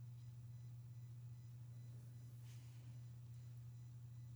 beeping noise coming up in recording
There is a consistent beeping in the background (sample attached) that I only hear when I record that I don’t think has been a problem before tonight.
Much louder than the “beeping” is 120Hz from mains hum, (1st harmonic of 60Hz).
That is USB leakage.